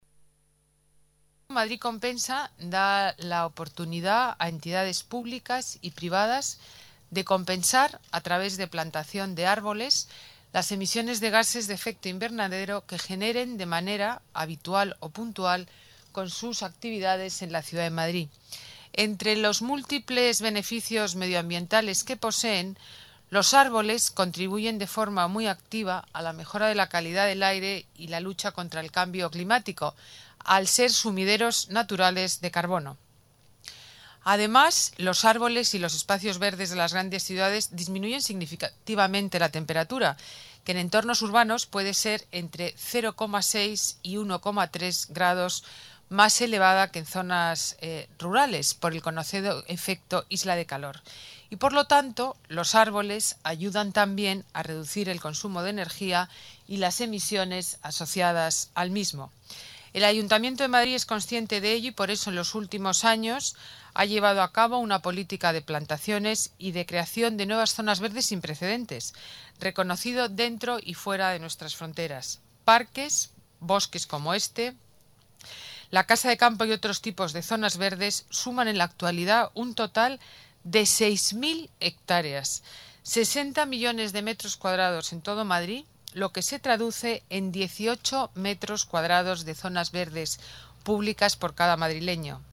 Nueva ventana:Declaraciones de la delegada de Medio Ambiente y Movilidad, Ana Botella: Madrid Compensa